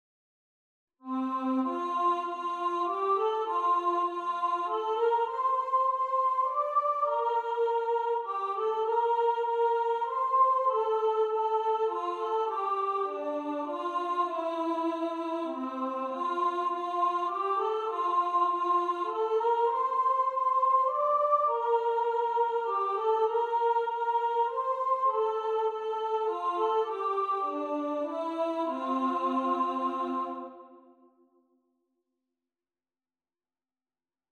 Away-In-A-Manger-Soprano.mp3